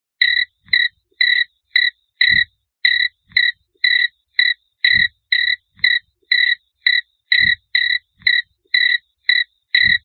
10 Secs pure Crickets